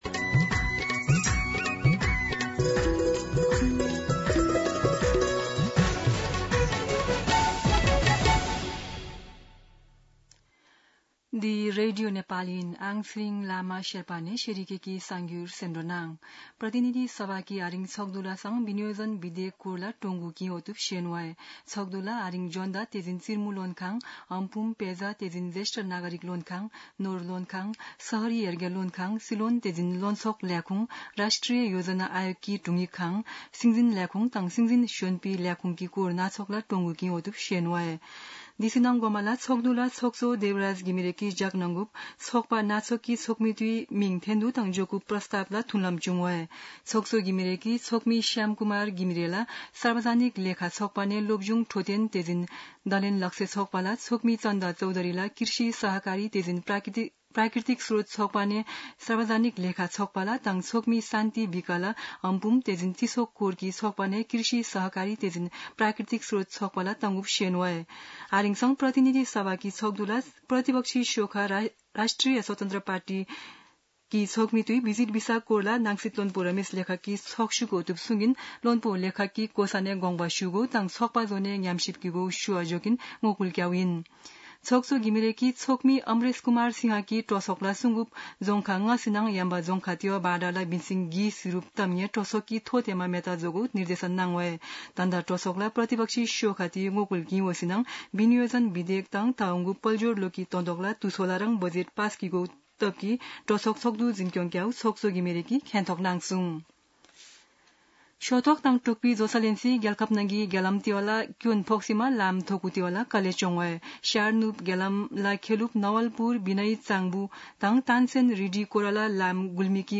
शेर्पा भाषाको समाचार : ८ असार , २०८२
Sherpa-News-08.mp3